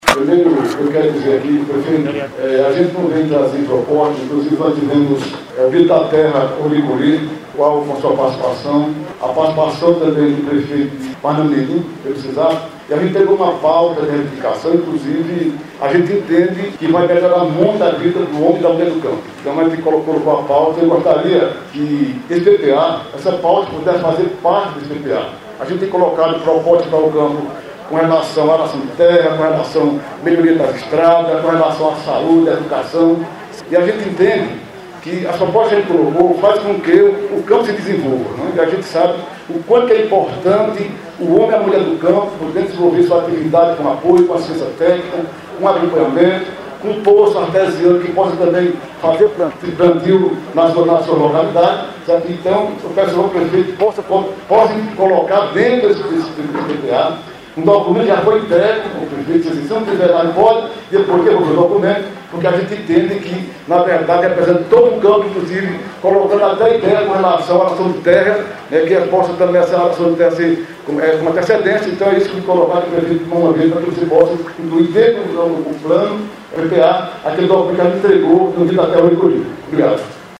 O evento ocorreu no Salão Paroquial da Matriz de São Sebastião no centro de Ouricuri.